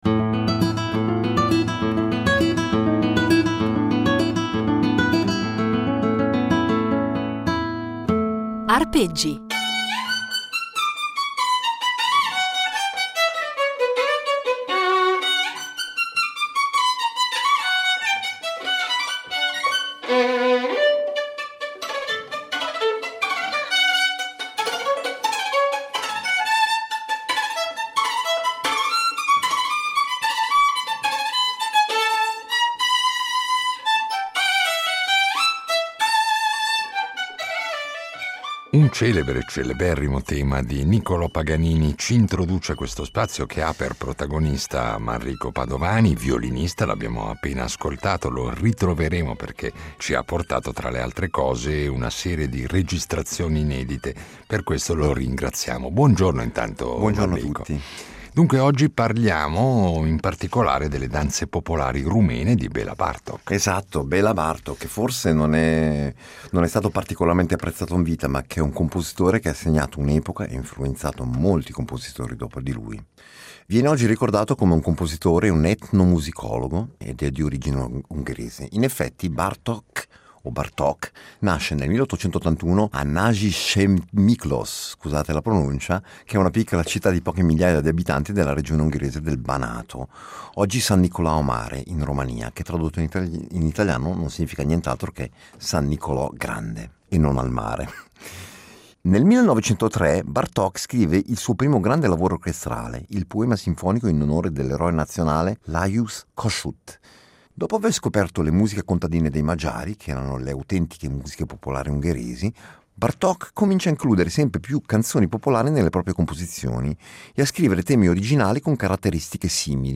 regalandoci delle registrazioni, perlopiù inedite, di brani